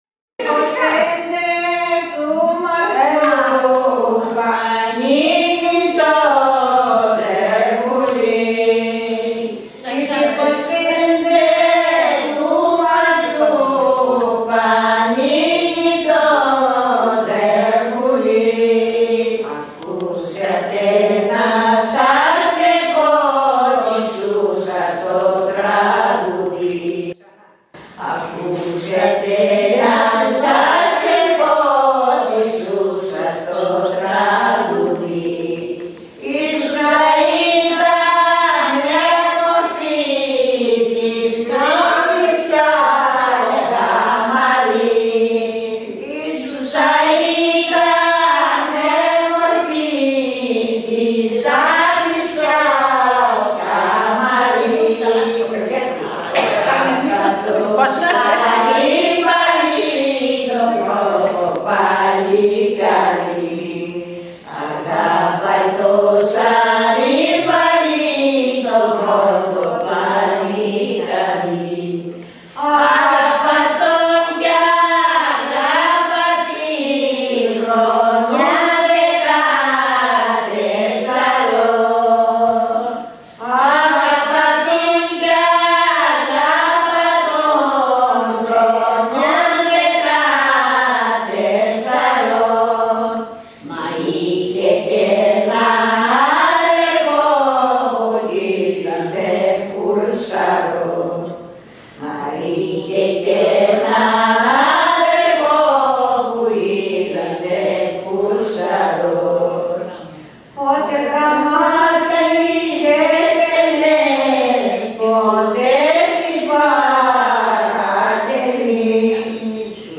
Η παραλλαγή της Αφησιάς.